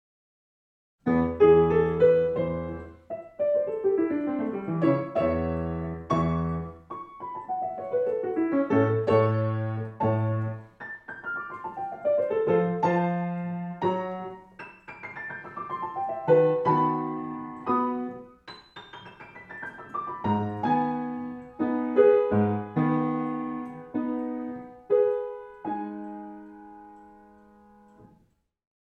Musik zum Mantra 11 L — spielerisch